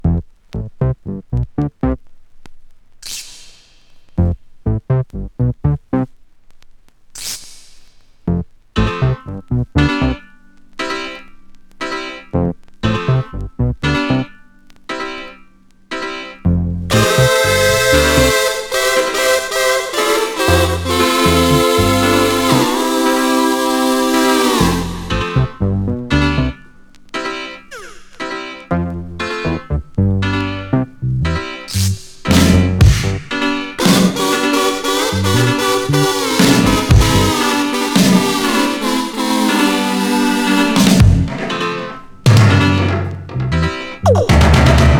基本的にベースは、作詞作曲、ヴォーカル、コーラス、演奏と自作自演の多重録音で、宅録×80’sニューウェーブポップス。
Pop, Rock, New Wave　Netherlands　12inchレコード　33rpm　Stereo